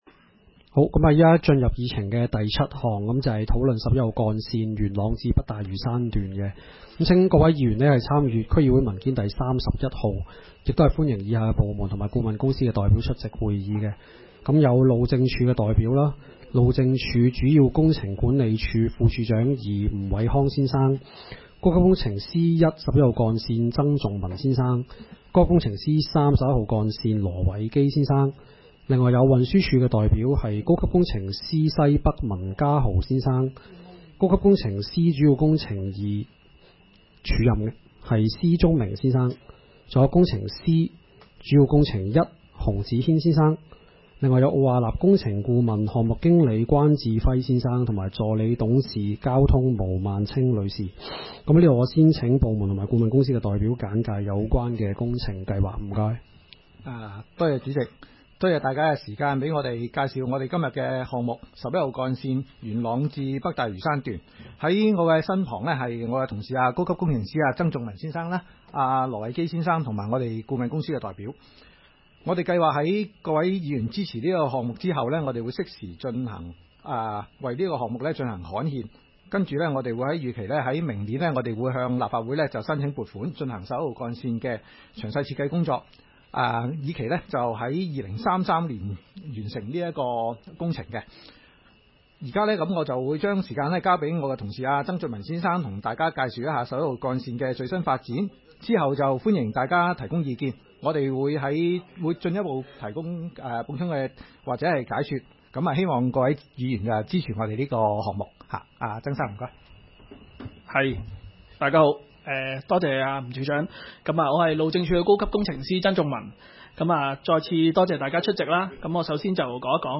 區議會大會的錄音記錄